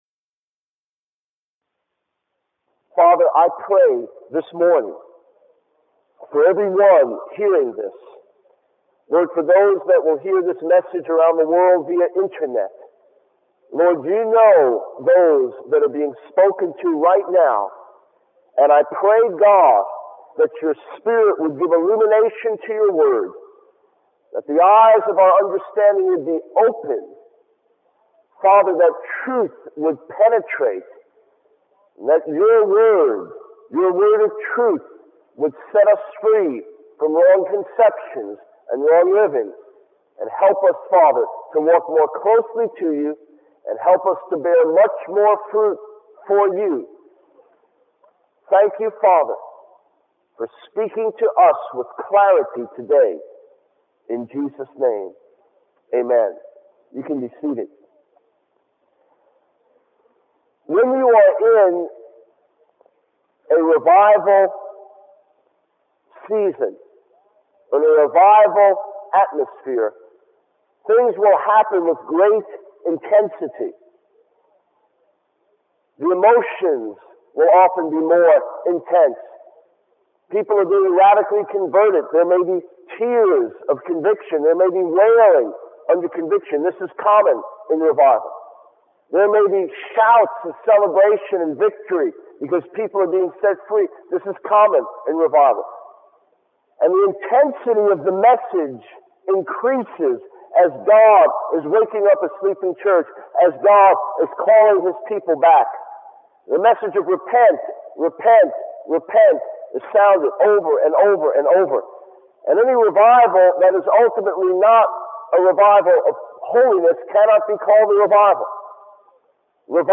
In this sermon, the preacher emphasizes the importance of understanding God's posture towards believers. He explains that God desires to bless his children and wants them to live a righteous life. The preacher also highlights the process of transformation that occurs through the blood of Jesus, where believers are changed from death to life and from the kingdom of Satan to the kingdom of God.